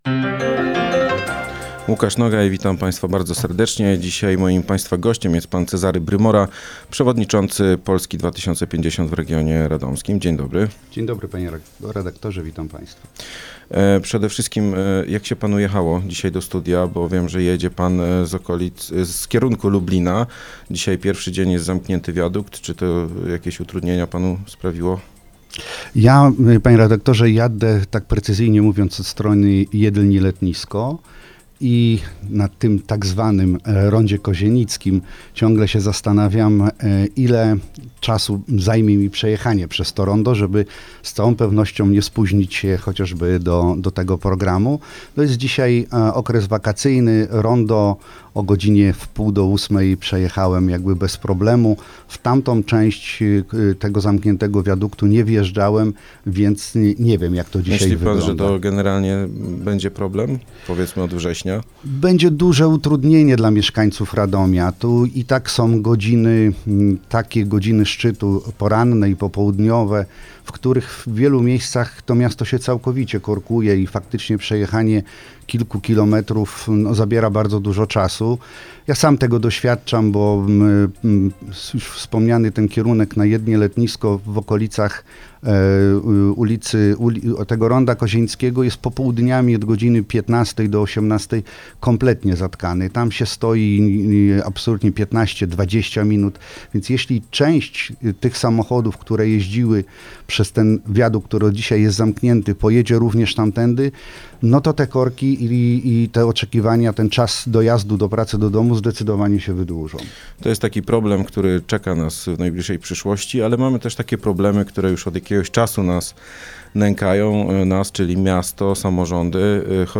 Rozmowa dostępna również na facebookowym profilu Radia Radom: